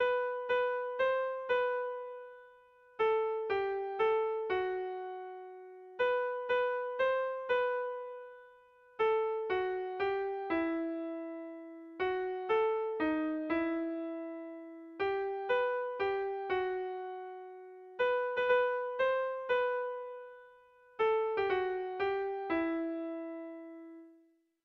Lau puntuko berdina, 8 silabaz
A1A2BA2